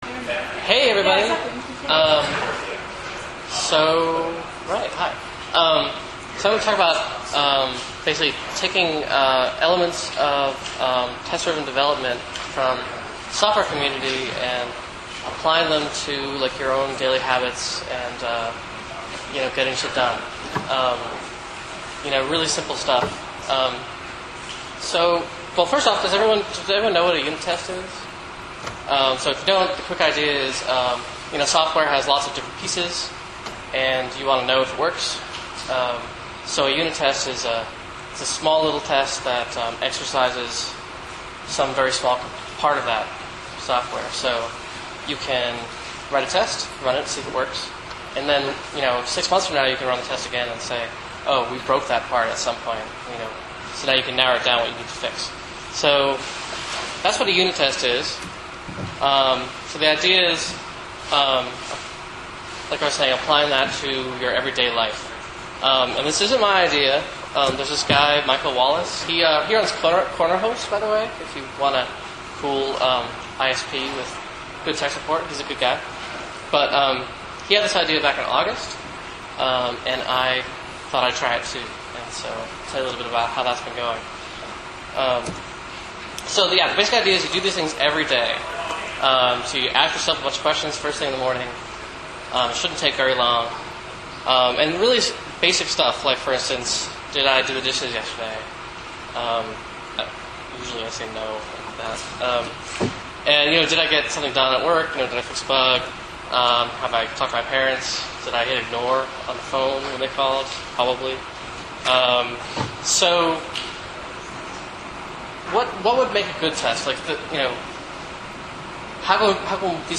BarCamp Los Angeles 4